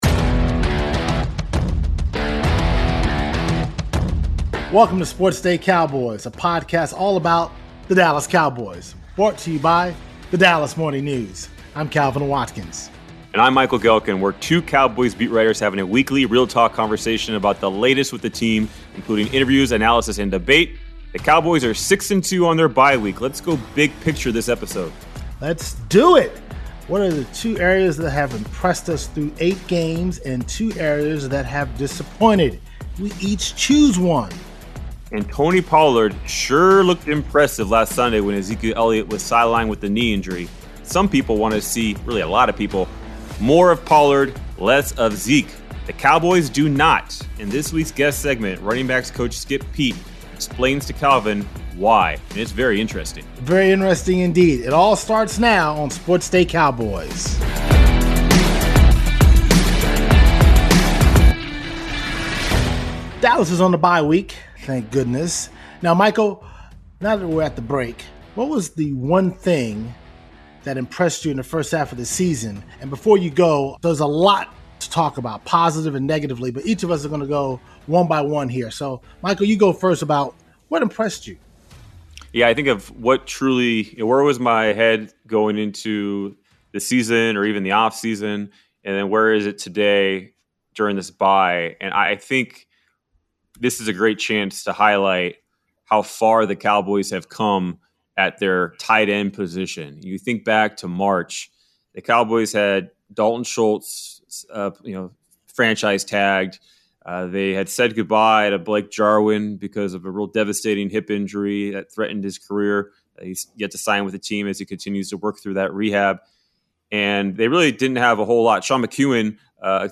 The Cowboys appear to be building in advance of the playoffs, as opposed to last season when the offense fizzled out. And reinforcements are on the way, beyond the potential addition of free agent receiver Odell Beckham Jr. The guys discuss OBJ’s visit with the Cowboys and break down the issues resented by Beckham’s knee injury.